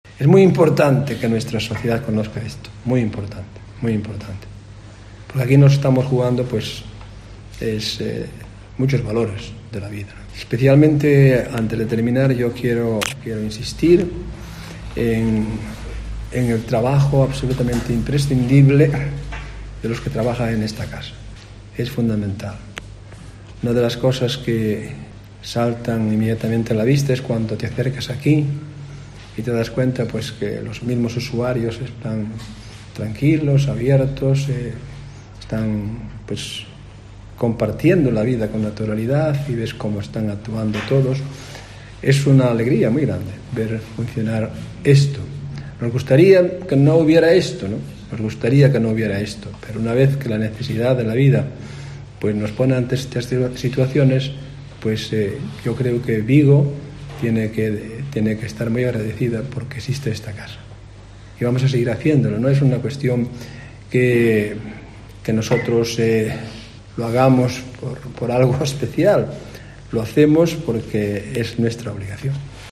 Con ellos trabajan día a día 23 personas a los que el obispo de Tui - Vigo, Monseñor Don Luis Quinteiro Fiuza quiso dedicar estas palabras:
Monseñor Don Luis Quinteiro Fiuza agradece la labor de los trabajadores de la Casa Nosa Señora da Saúde